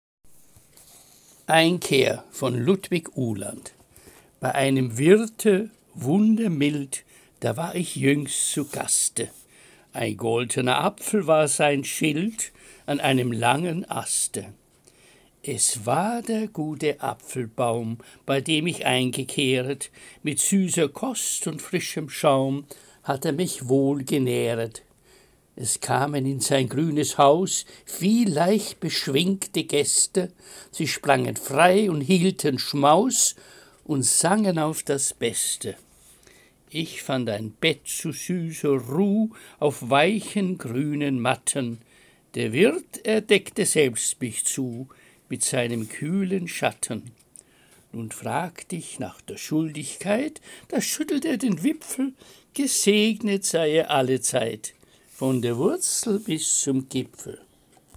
Lesung Gedichte aus der Romantik